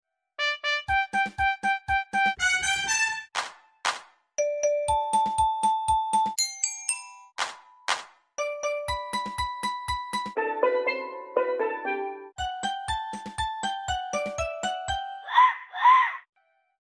MARBLE vs REAL instruments!